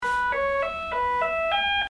Hospital infusion machine: air bubbles alert sound
hospitalbeep.mp3